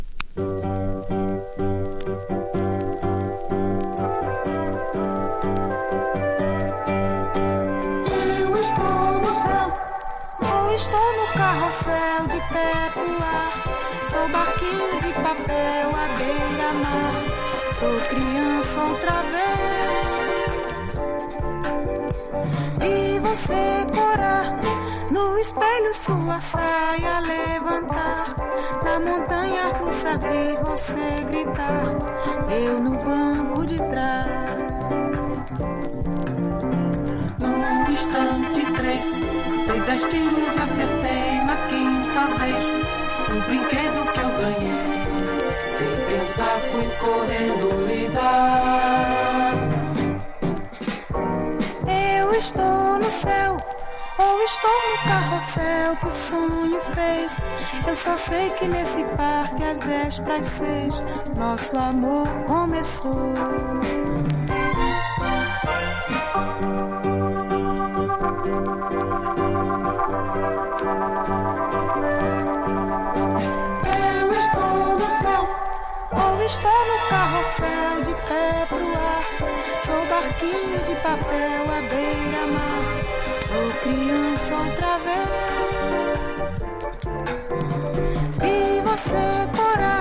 RARE GROOVE# FUNK / DEEP FUNK